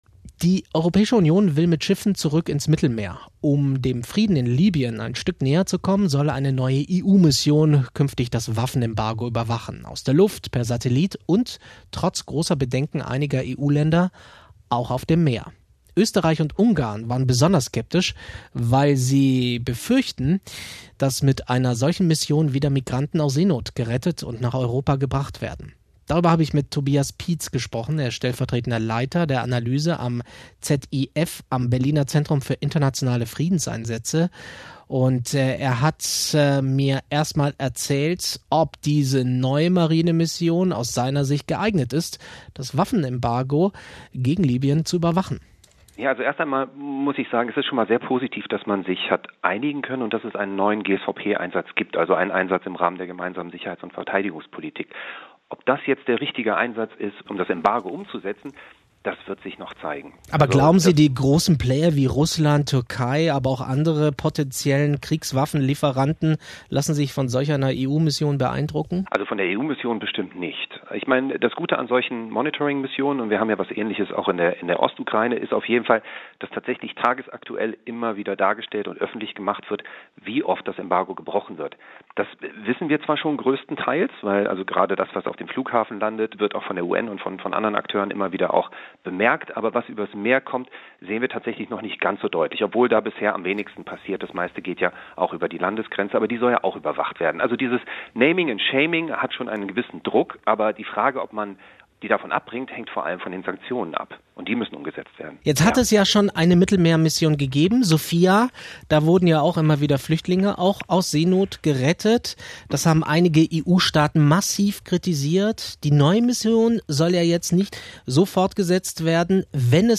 Inforadio | Interview mit Inforadio zur neuen EU-Mission | ZIF Berlin